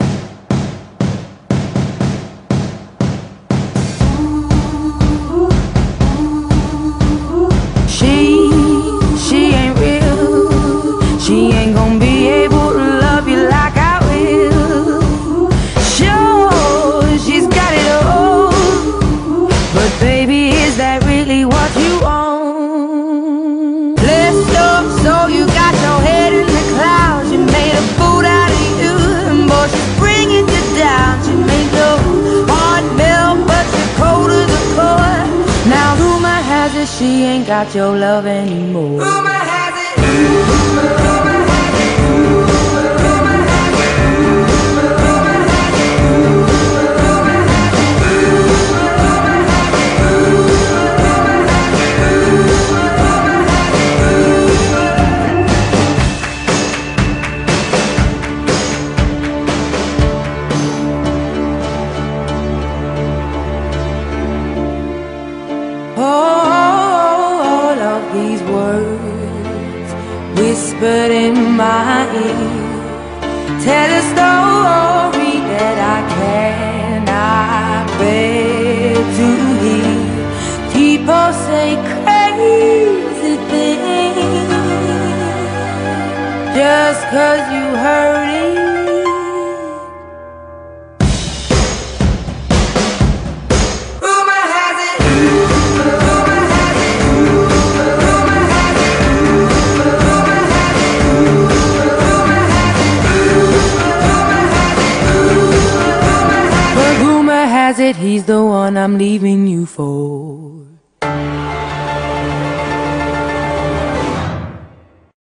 BPM36-120
Audio QualityMusic Cut